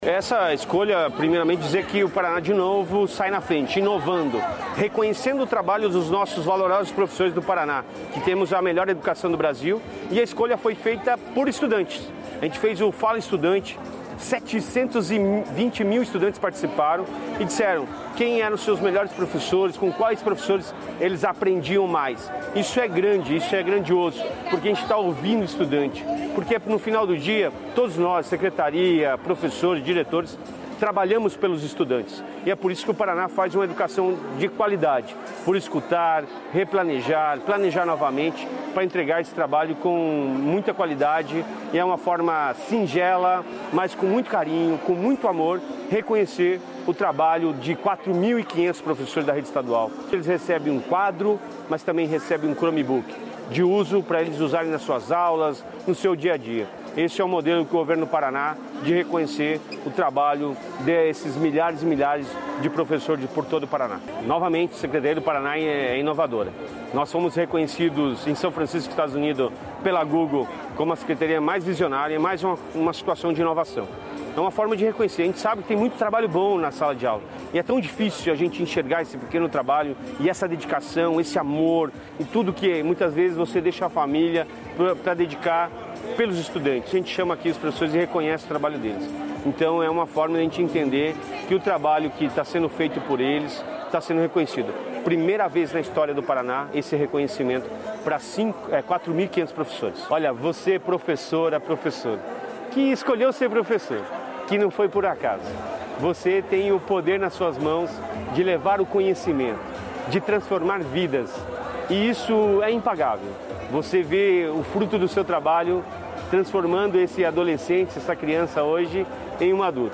Sonora do secretário da Educação, Roni Miranda, sobre a homenagem aos educadores